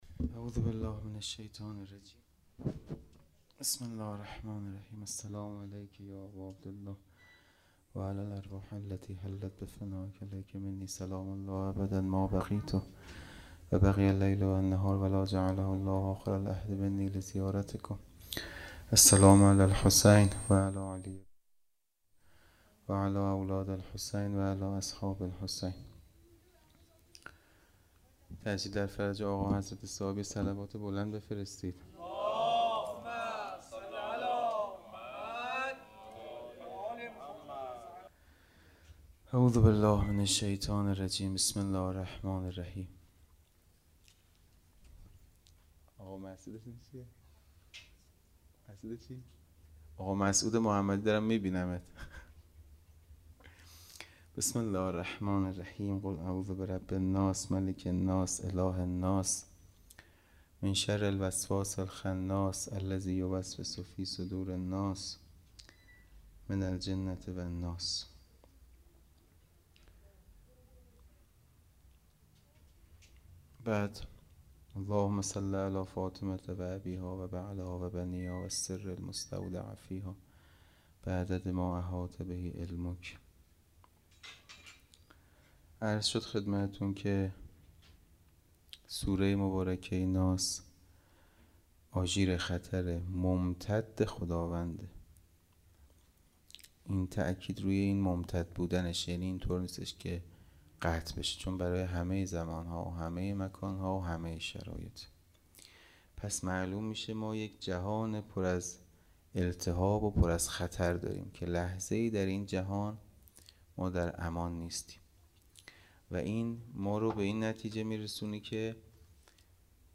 خیمه گاه - حسینیه کربلا - شب دوم محرم- سخنرانی